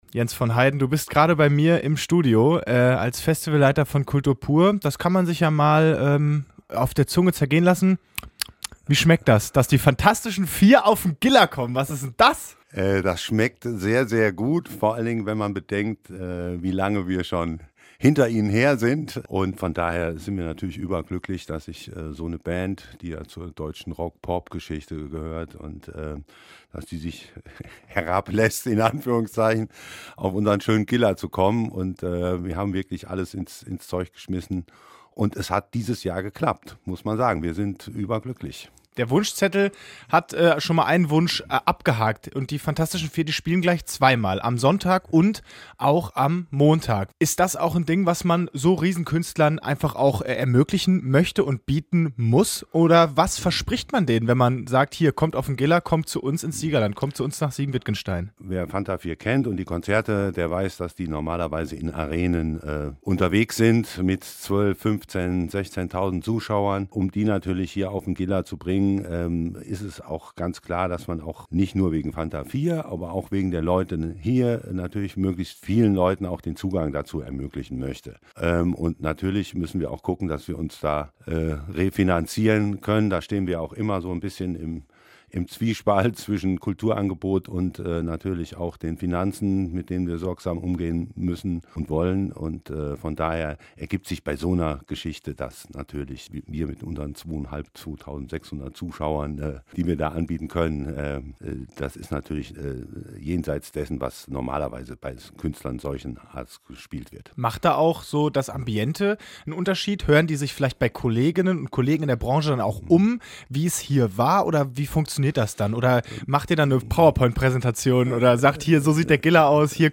Kultur Pur 32 Interview